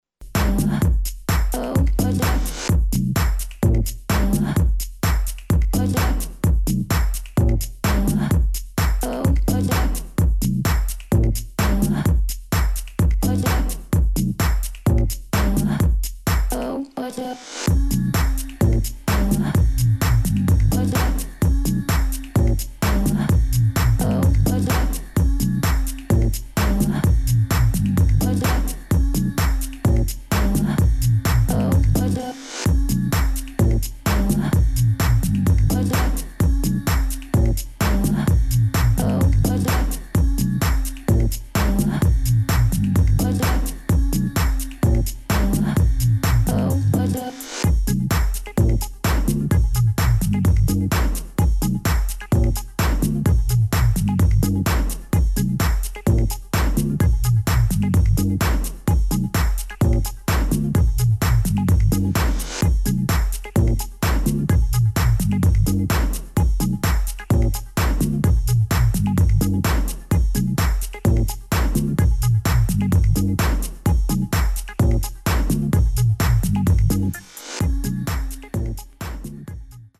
[ BASS ]
DUBSTEP | BASS